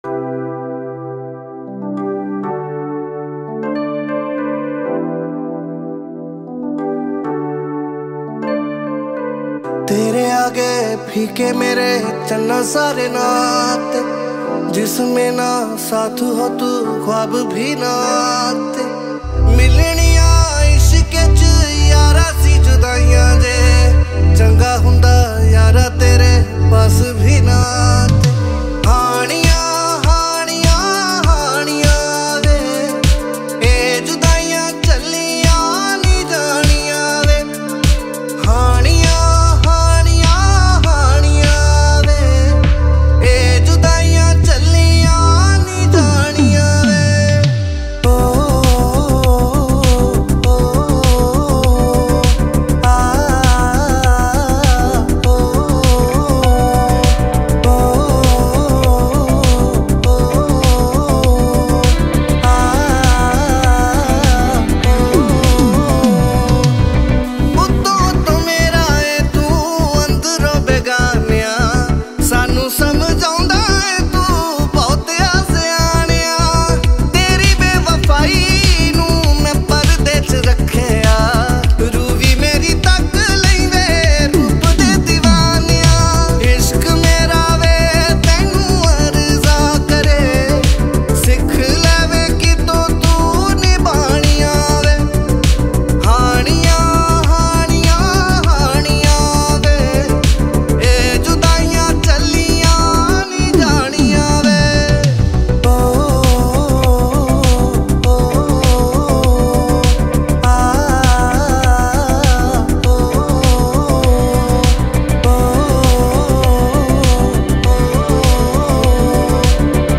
2. Punjabi Single Track